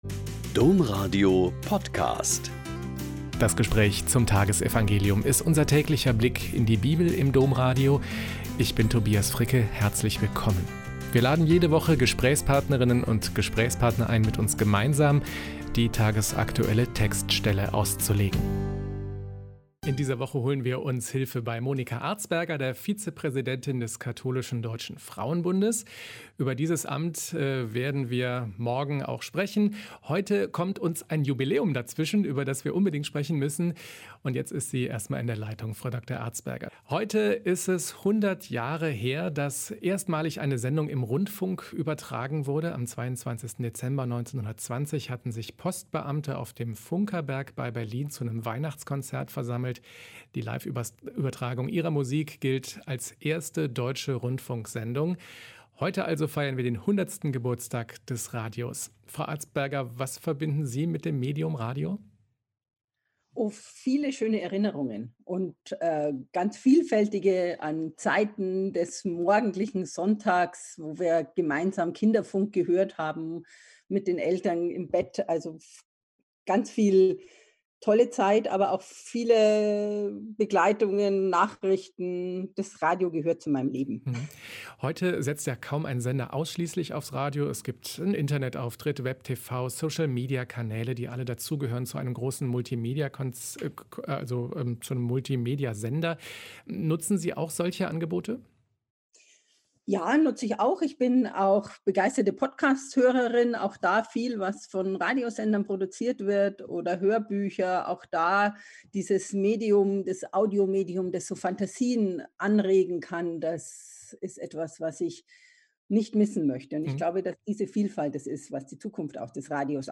Lk 1,46-56 - Gespräch